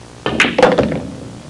Ball In Side Pocket Sound Effect
ball-in-side-pocket.mp3